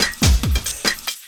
04LOOP01SD-L.wav